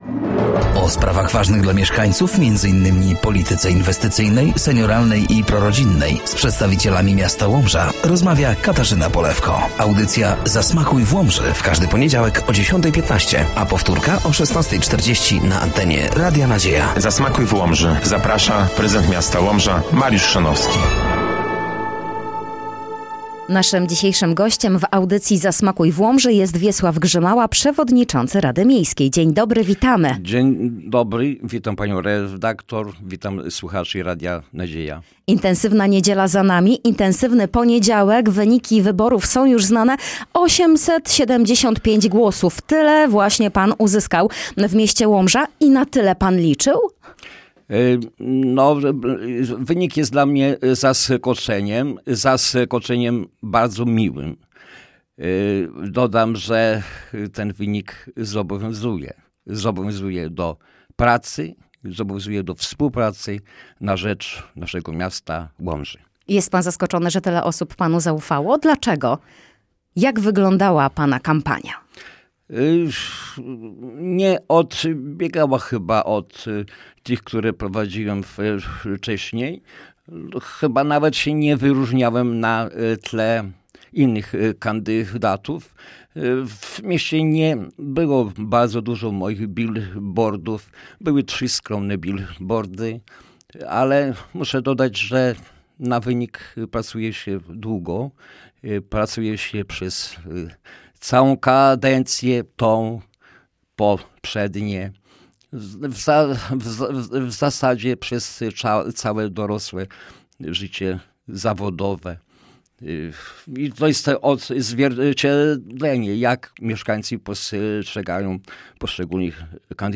Gościem dziewiątej audycji był Wiesław Grzymała – przewodniczący Rady Miejskiej w Łomży.